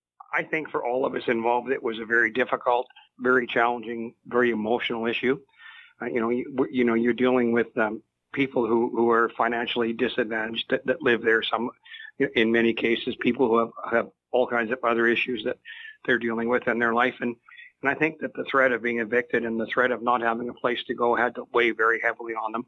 Mayor Larry Jangula says there has been a lot of interest in Maple Pool since the dispute began back in 2010.